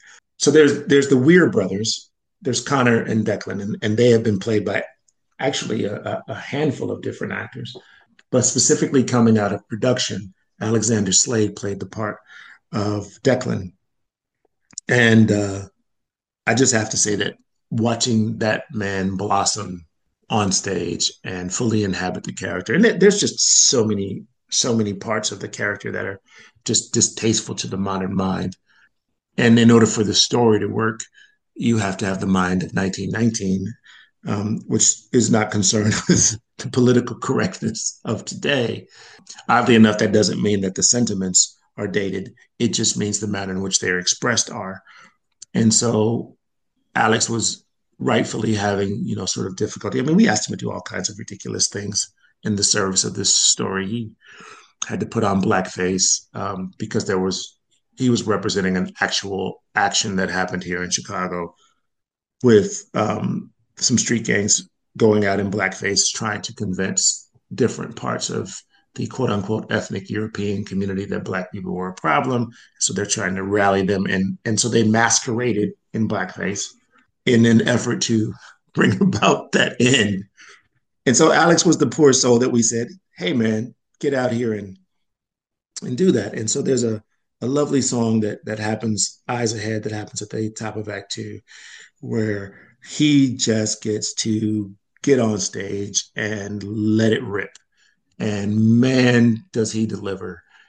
Join our songwriters and performers as we look to the exciting growth of the productions and the crafting of new songs for RED SUMMER.